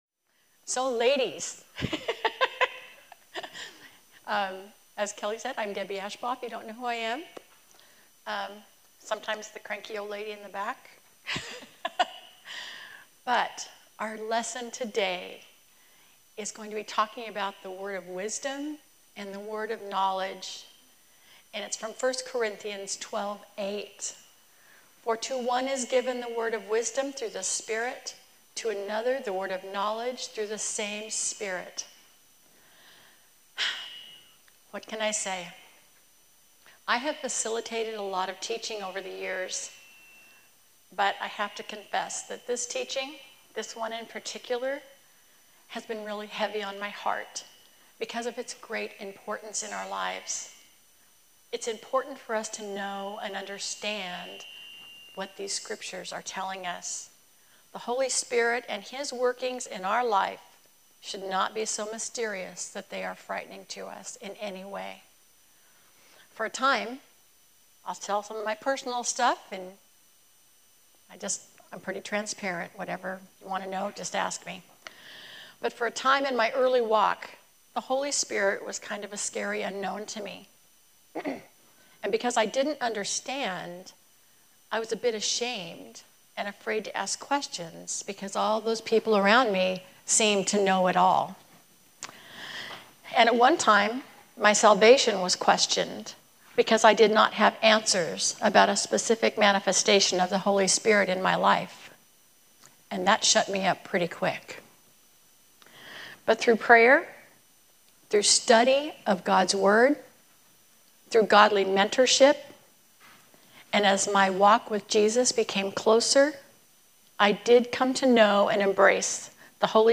A message from the series "Women of the Word."